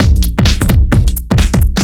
OTG_DuoSwingMixA_130a.wav